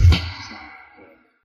Aunt Wang Syrup Theme Song Snare2.wav